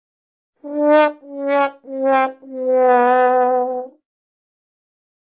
TROMBONE SAD, FAILURE - Sound Effect - Free Download